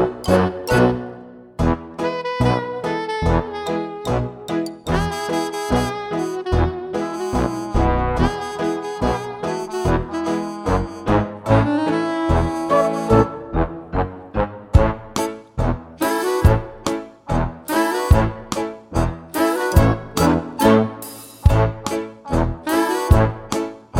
no Backing Vocals Musicals 4:58 Buy £1.50